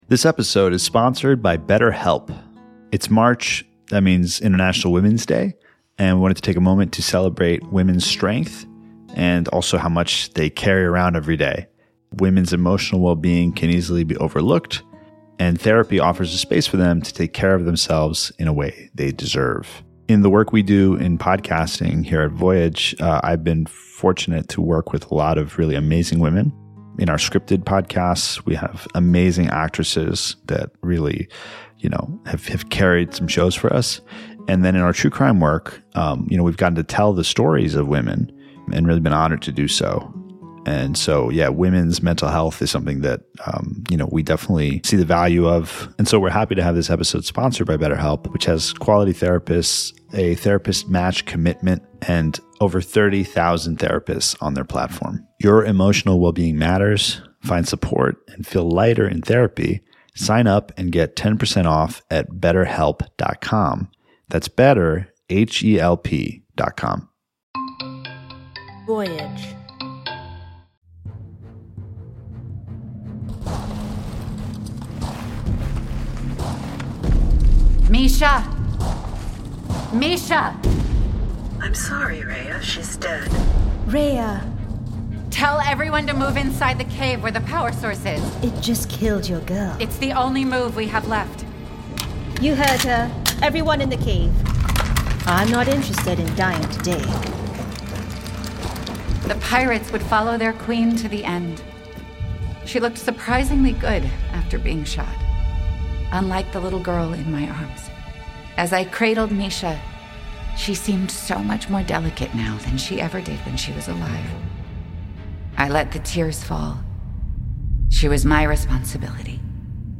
Starring Lily Rabe, as Reya.